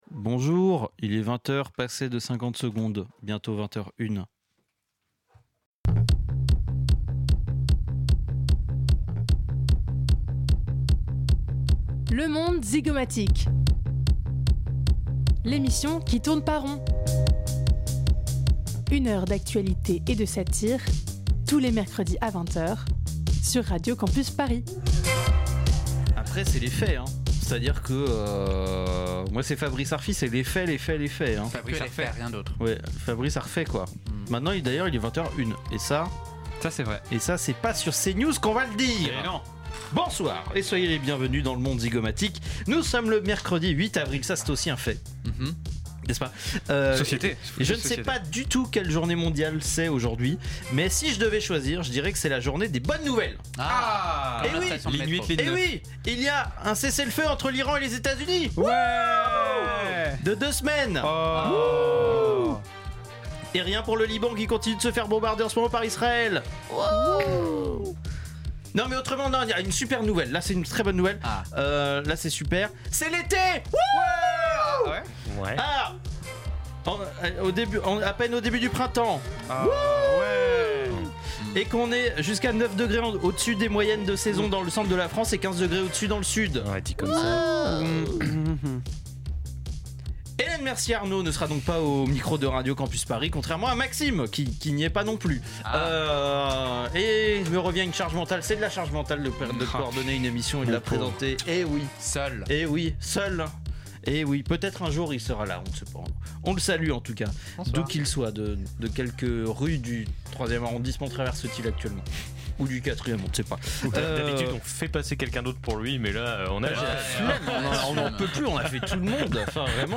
Type Magazine Société